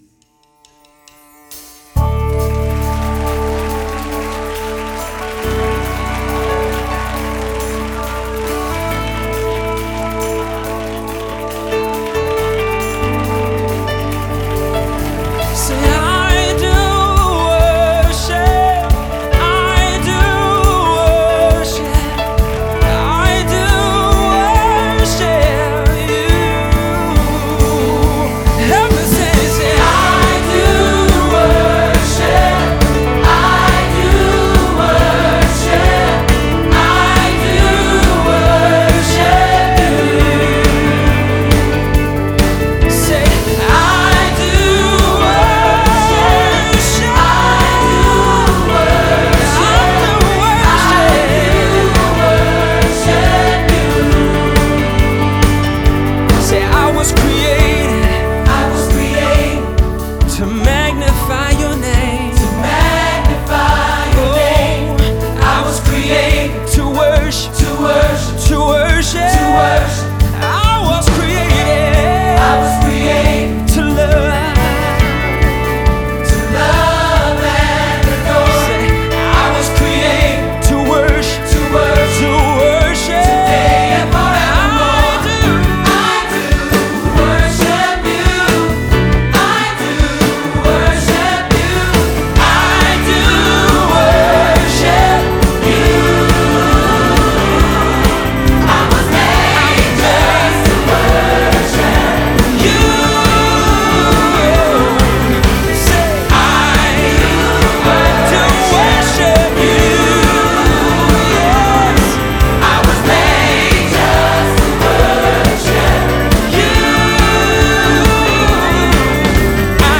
장르: Funk / Soul, Pop
스타일: Gospel, Vocal